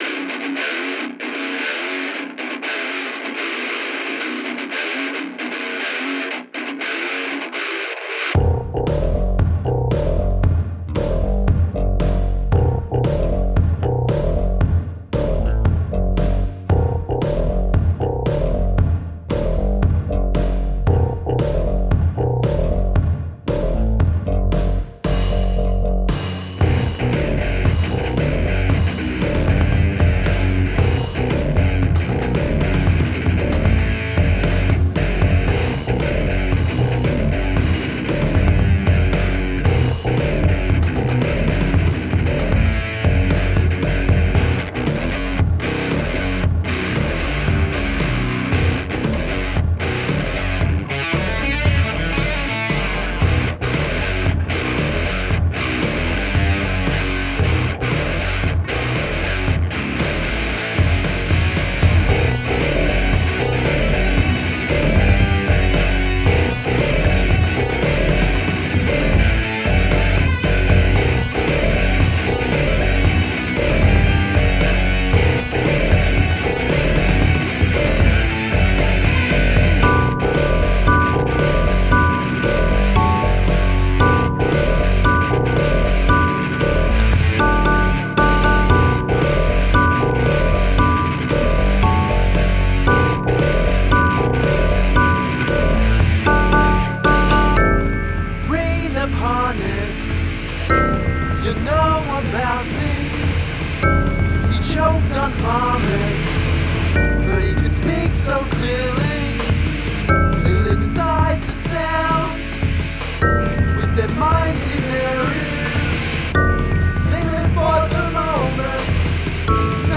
Electronic, Jazz (2023)